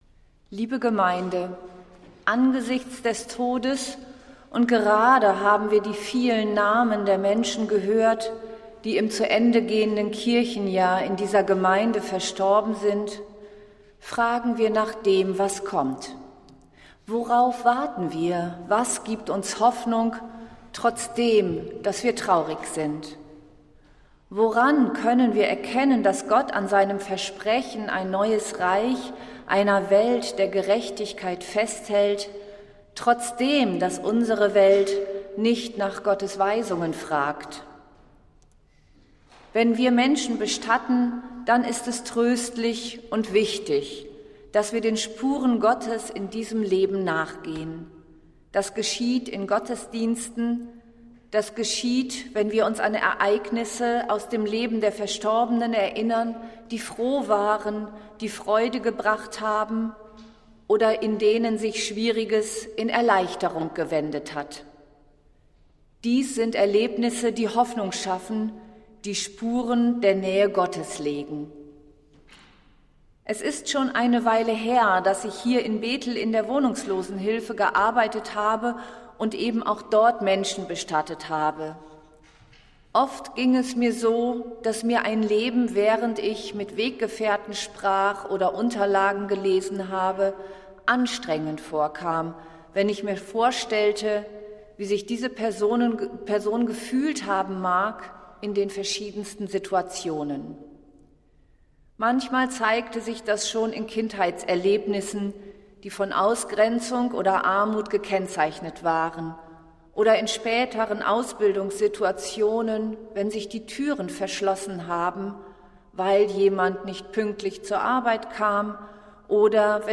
Predigt des Gottesdienstes aus der Zionskirche vom Sonntag, 23. November 2025